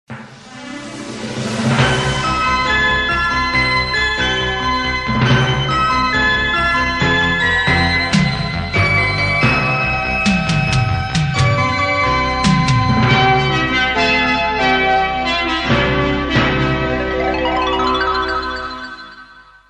Sintonia institucional de l'emissora.